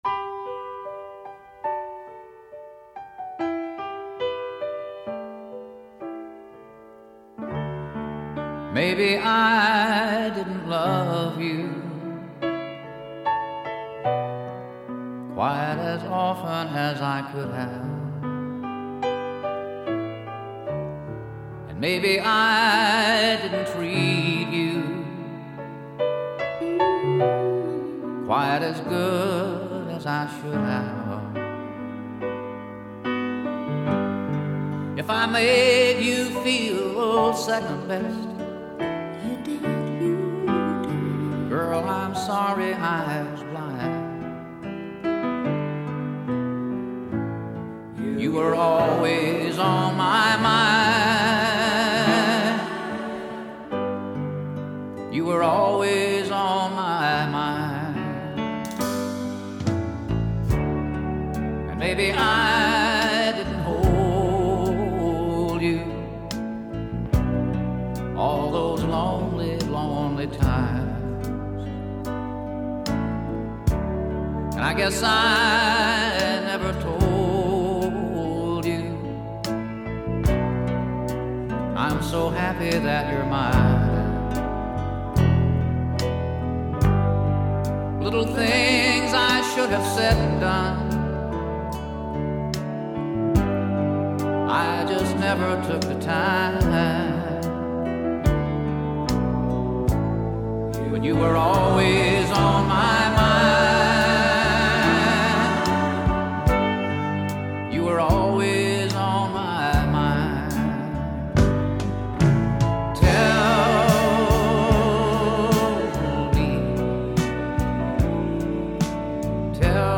그의 곡들은 감성적인 가사와 따뜻한 보컬로 특징지어지며, 이 곡도 예외가 아닙니다.